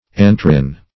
Search Result for " antiarin" : The Collaborative International Dictionary of English v.0.48: Antiarin \An`ti*a*rin\, n. (Chem.) A poisonous principle obtained from antiar.